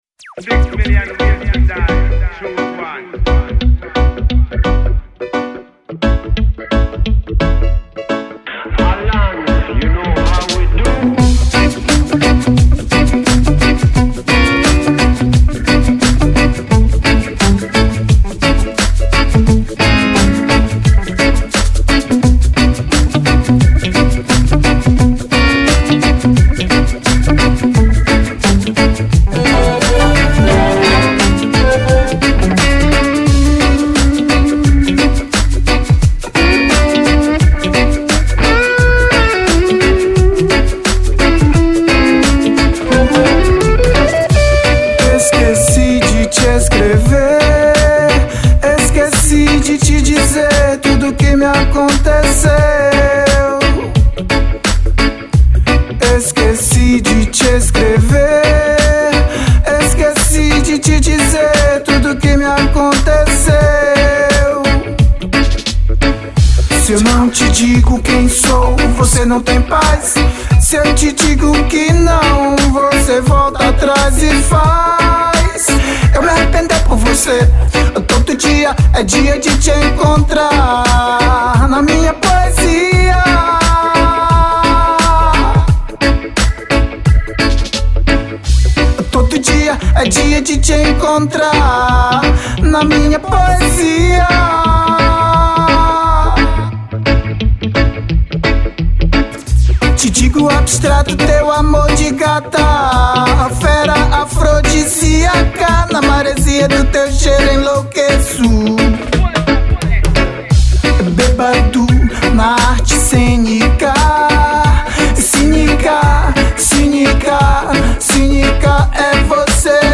06:27:00   Reggae